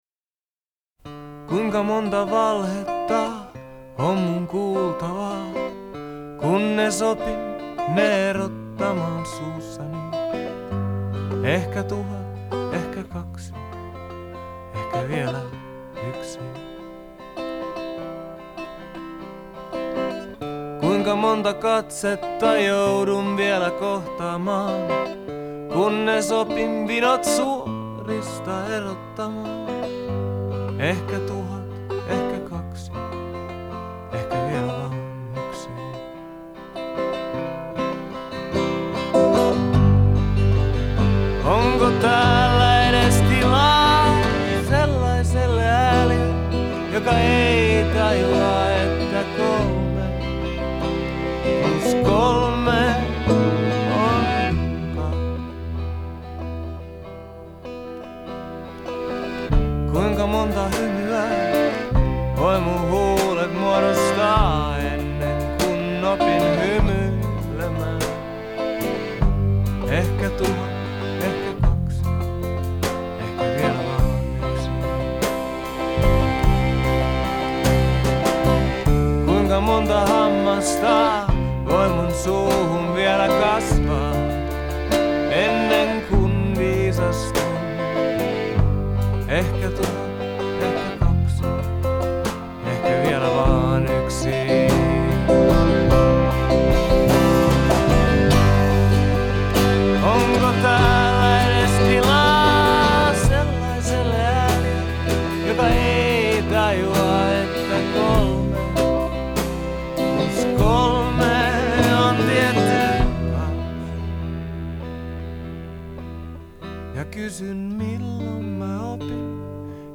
вокал и гитара
аккордеон и вокал
- барабаны
Genre: Folk, World